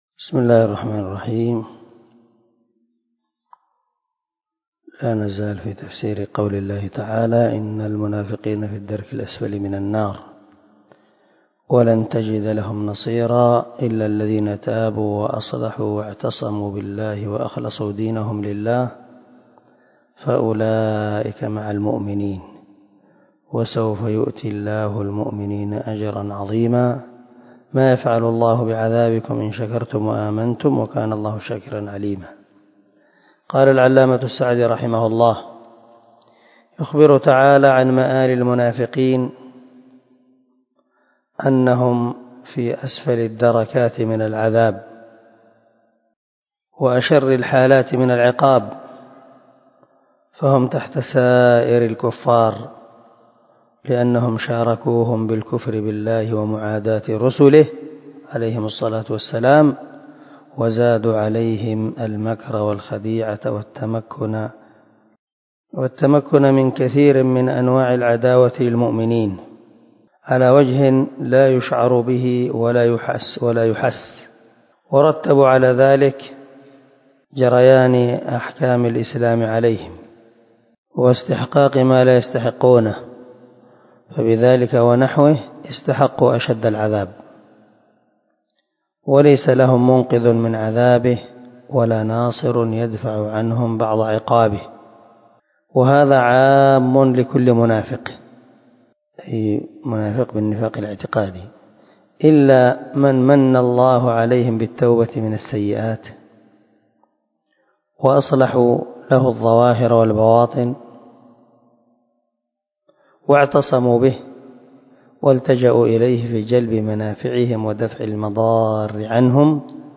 321الدرس 89 تابع تفسير آية ( 145-147 ) من سورة النساء من تفسير القران الكريم مع قراءة لتفسير السعدي
دار الحديث- المَحاوِلة- الصبيحة.